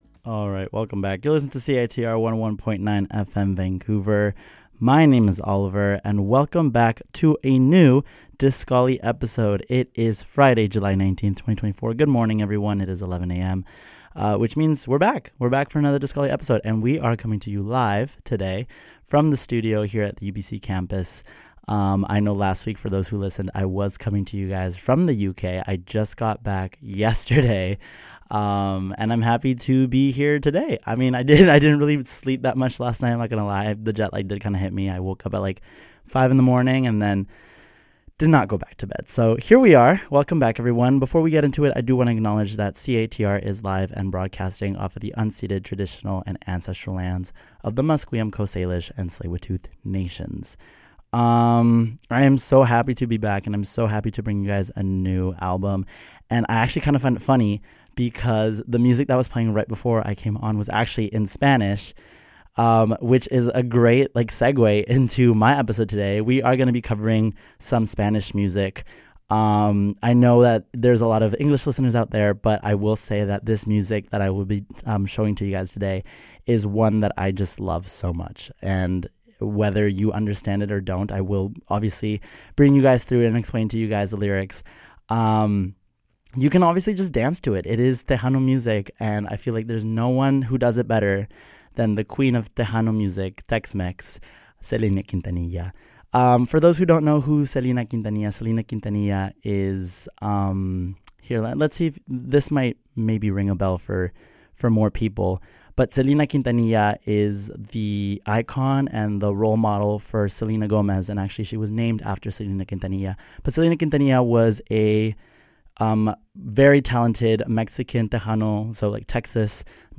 This episode takes you through the wonders and exciting music that come with the genres of Tejano pop and Regional Mexican.